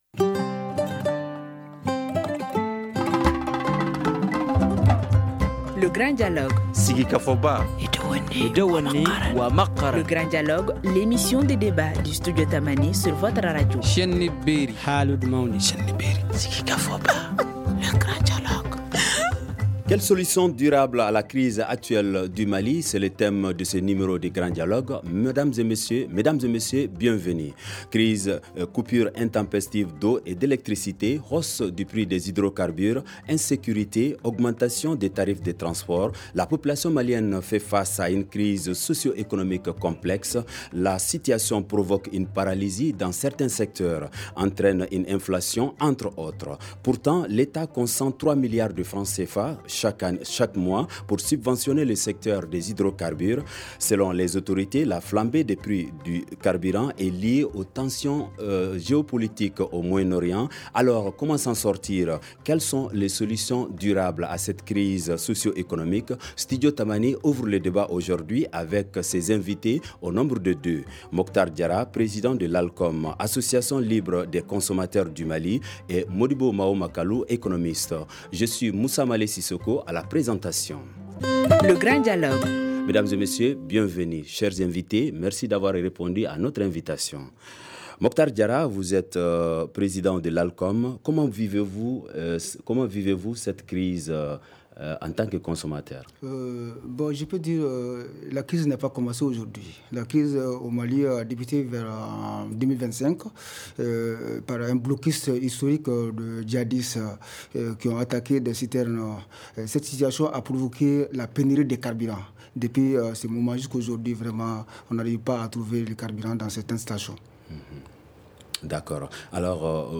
Quelles sont les solutions durables à cette crise socio-économique ? Studio Tamani ouvre le débat avec ses invités au nombre de deux (2).